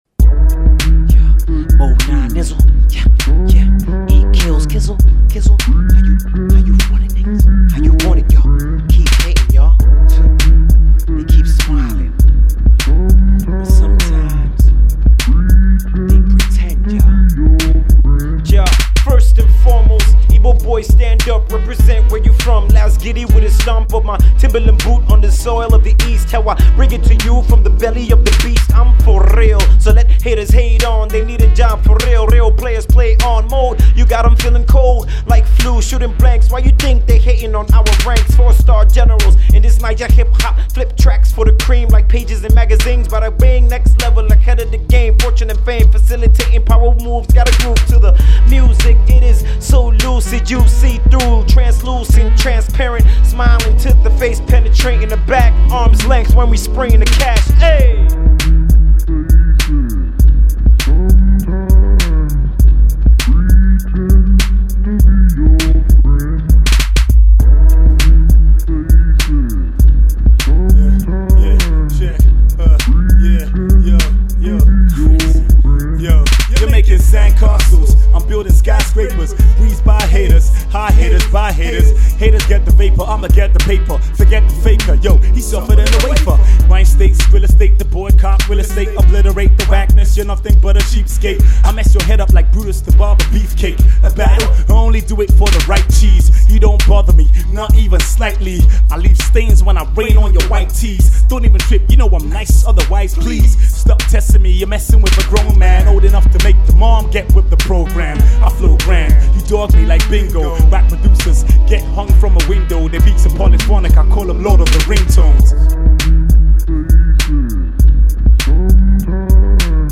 Hip-Hop
a Lyrical Showdown
sounds Fresh as Hell